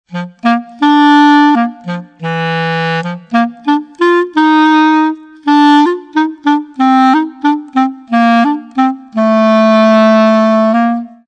CLARSOLO.mp3